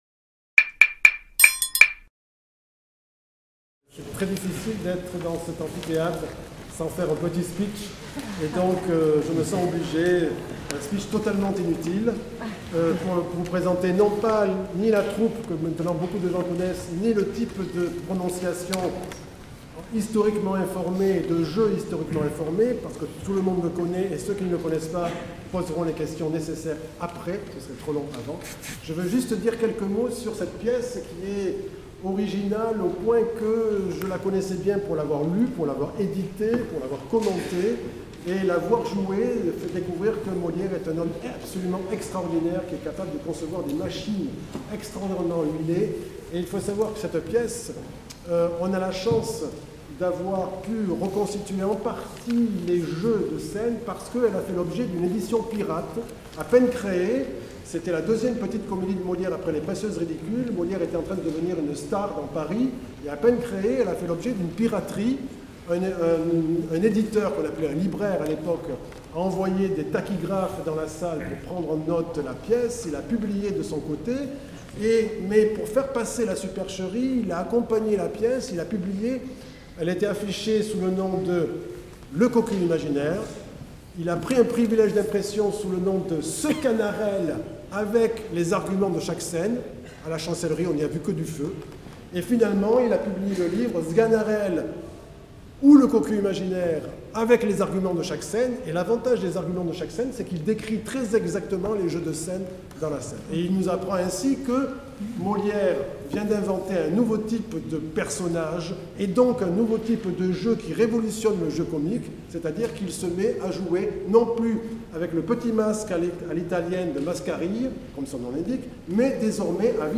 2) (4'45) premier extrait de la pièce.
conférence cultureGnum proprement dite
4) (15') second extrait de la pièce.